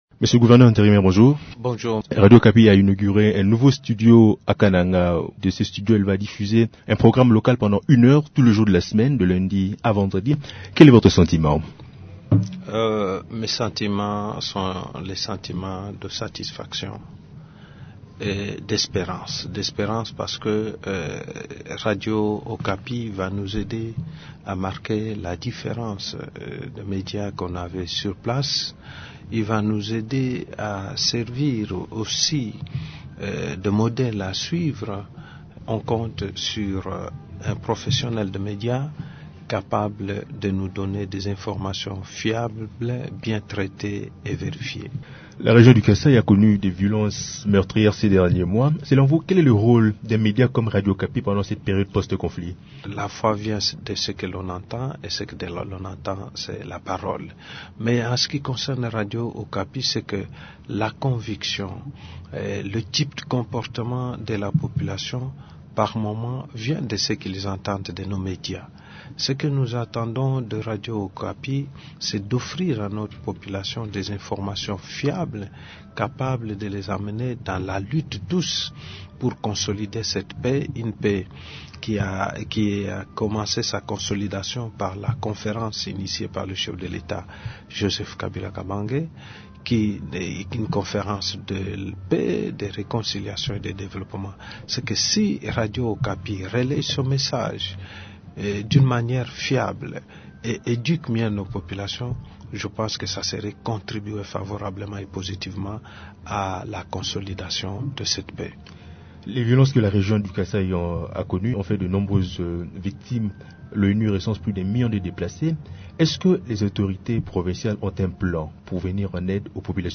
Le gouverneur intérimaire du Kasaï-Central est l’invité de Radio Okapi, mercredi 4 octobre.